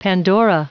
Prononciation du mot pandora en anglais (fichier audio)
Prononciation du mot : pandora